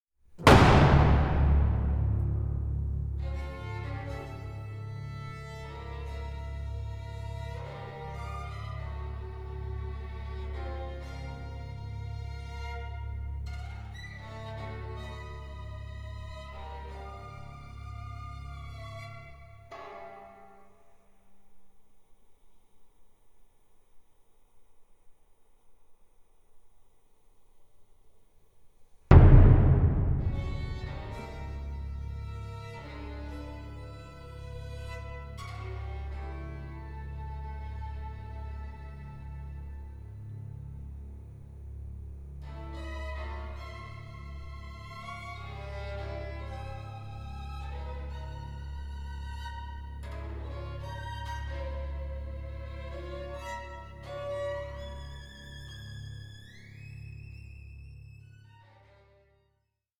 4) Lento (soloist plays offstage at the beginning) 6:21